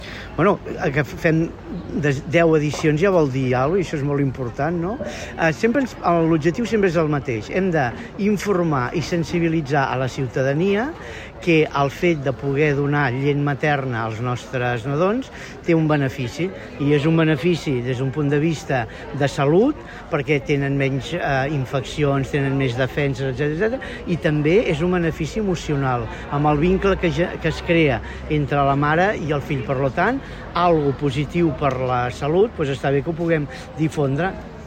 Aquest matí s’ha fet a la plaça de Les Cultures de Martorell la inauguració de la 10a Setmana de la Lactància Materna, que tindrà lloc del 20 al 24 d’octubre a diferents poblacions del Baix Llobregat Nord, entre elles Martorell, amb ponències i activitats per promoure l’alletament.